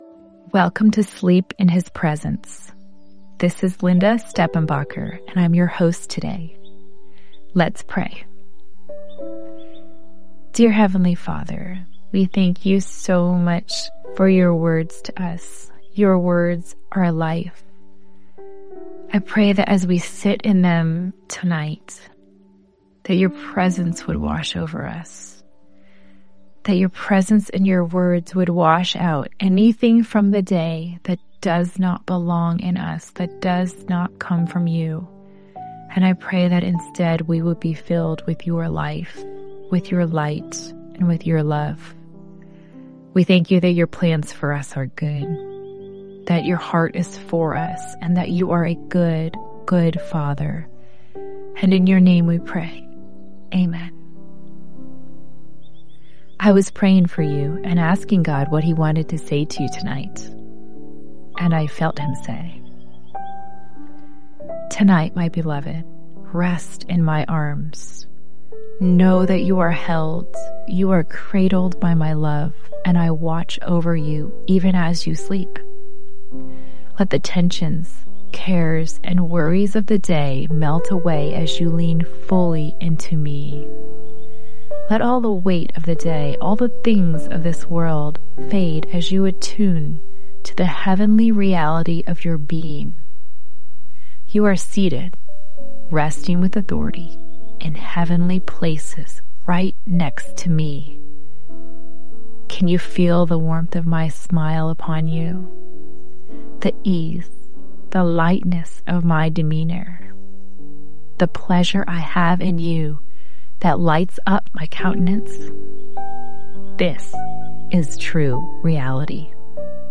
In this 6-day devotional, you’ll be gently led into the presence of God through heartfelt prayers, encouraging words, and powerful Scripture. Each night, a different host guides you to release your worries, embrace God’s love, and fall asleep knowing He is near.